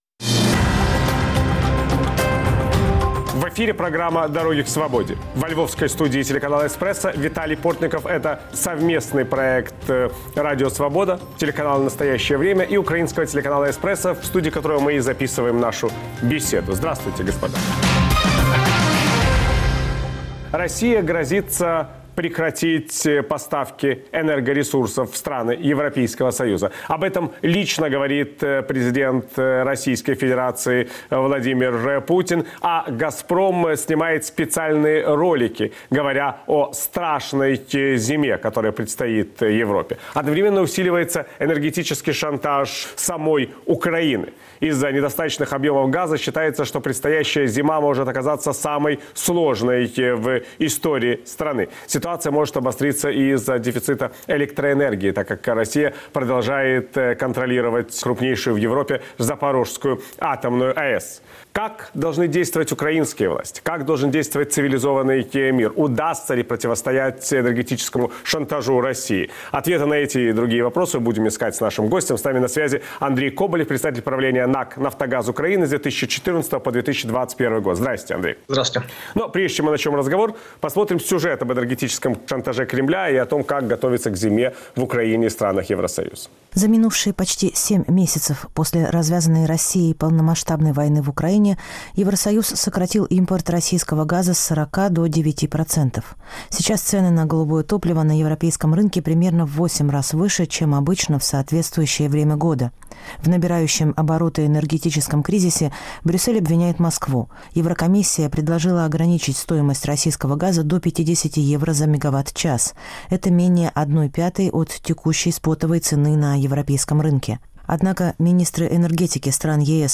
Почему в Европе не прислушивались к предупреждениям из Киева? Виталий Портников беседует с бывшим председателем правления "Нафтогаз Украины" Андреем Коболевым.